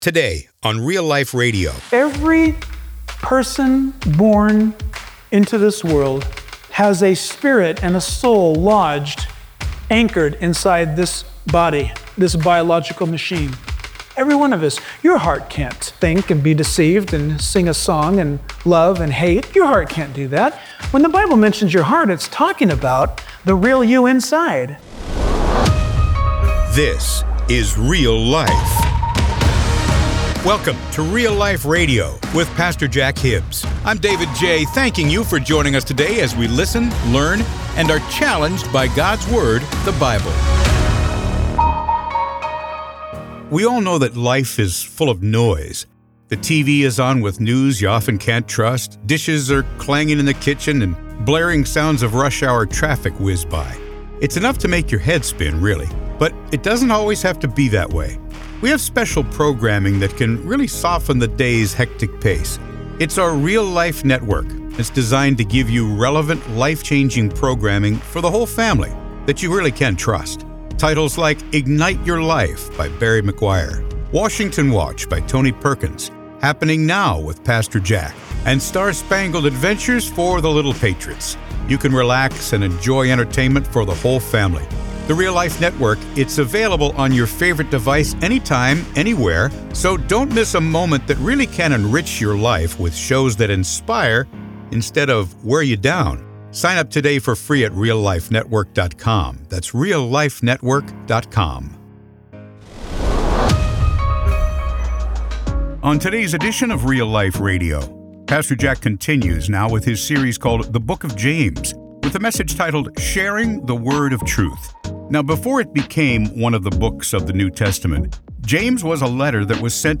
His bold preaching will encourage and challenge you to walk with Jesus.